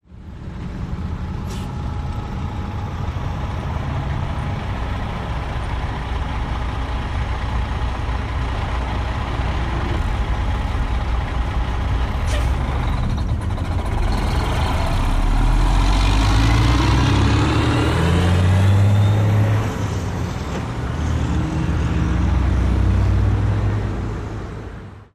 tr_dieseltruck_away_01_hpx
Diesel truck idles and then drives off with brake releases. Vehicles, Truck Engine, Motor